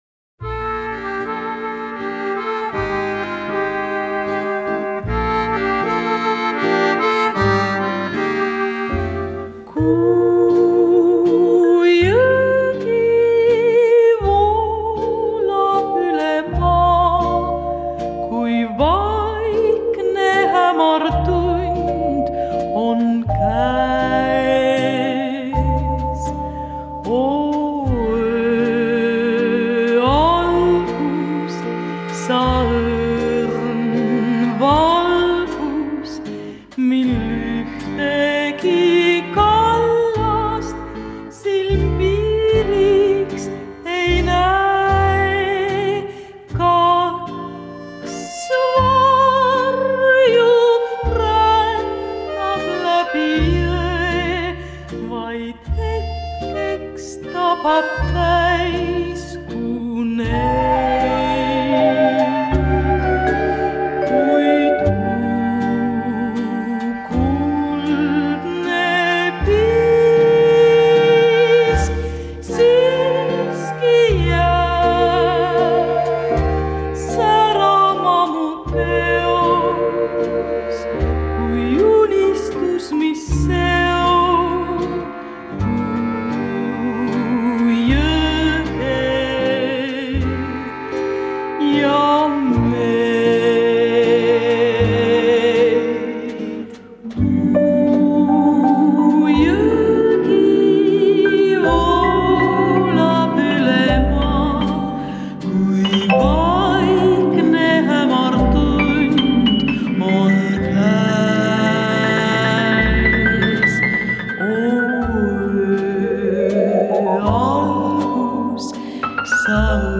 меццо-сопрано